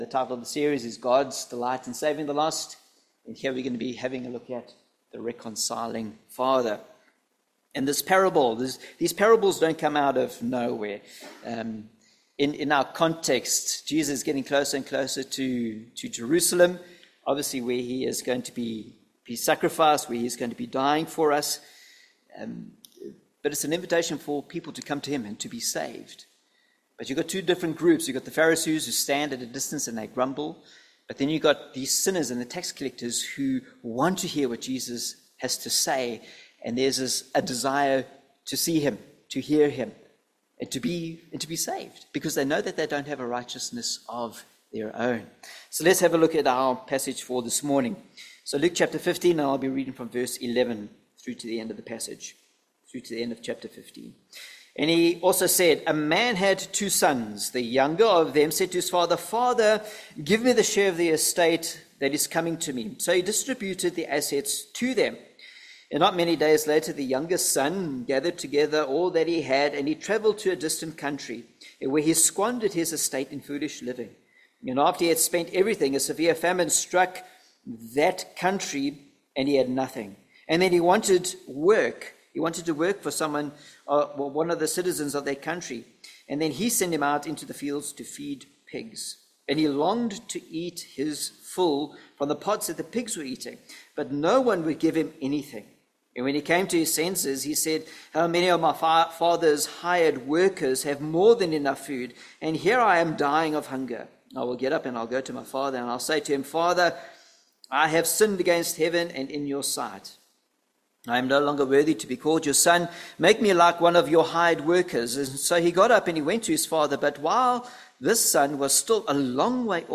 Luke 15:11-32 Service Type: Sunday Morning A Rebellious Son A Repenting Son A Reconciling Father « Who Can Ascend God’s Holy Mountain?